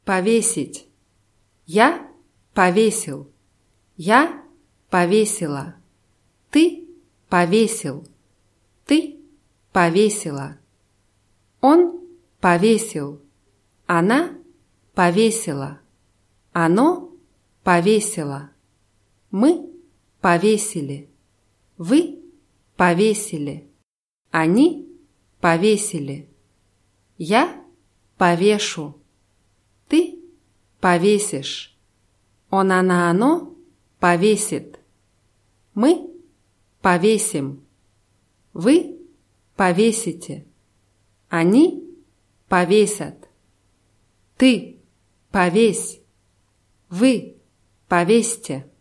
повесить [pawʲéßʲitʲ]